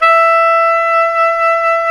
SAX TENORB18.wav